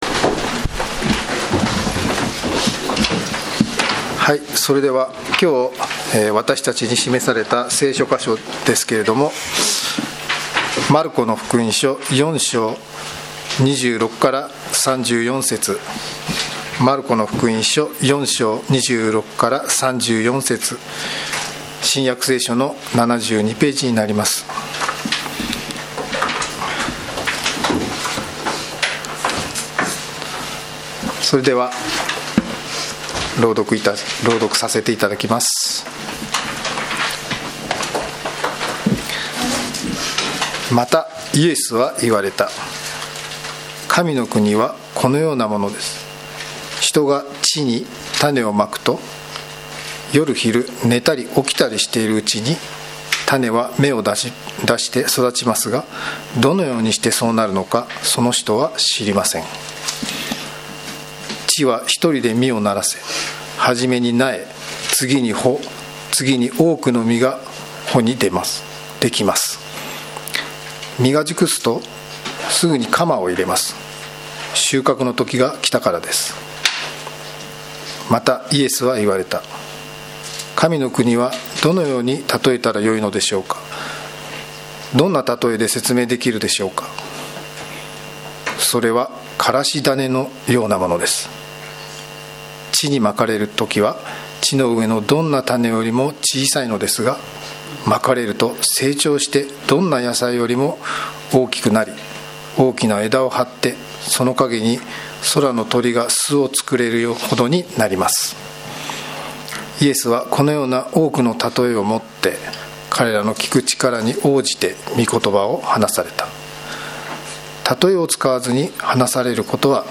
「礼拝拝メッセージ」